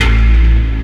bseTTE52015hardcore-A.wav